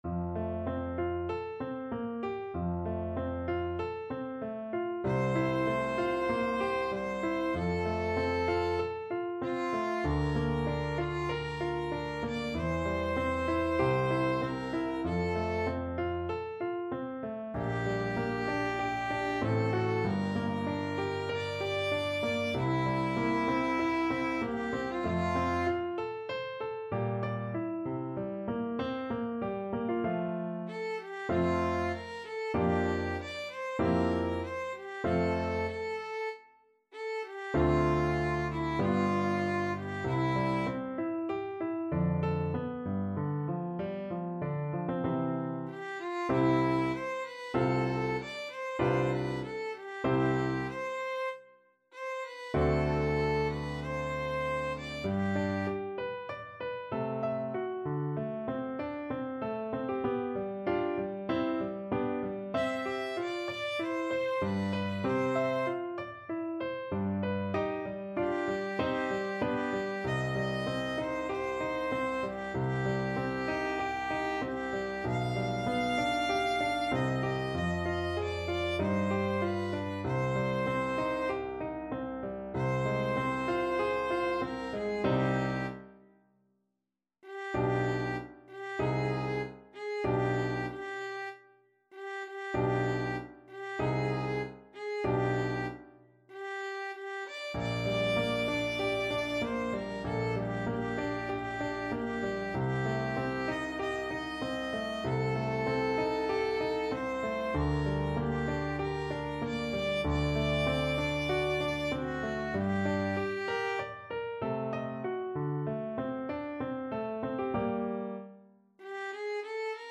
4/4 (View more 4/4 Music)
~ = 96 Andante
Classical (View more Classical Violin Music)